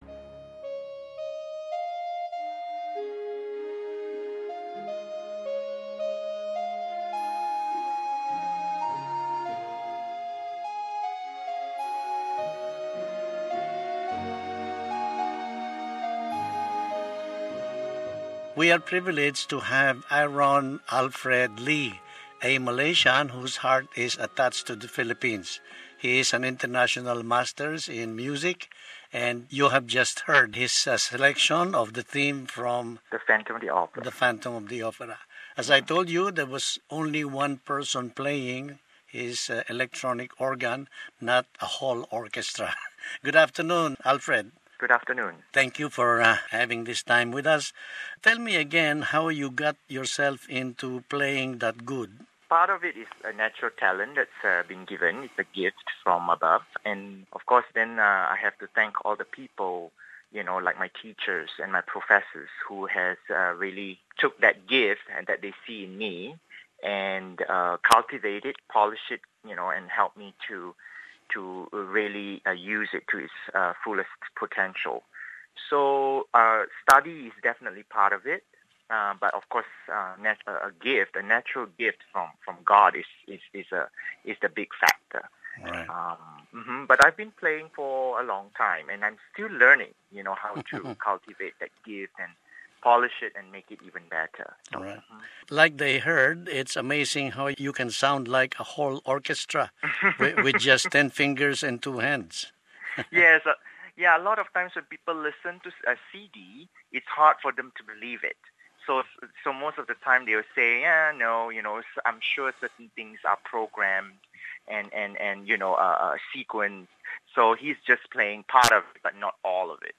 I cannot blame you if you thought that a whole orchestra played the sample music.